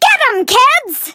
flea_ulti_vo_01.ogg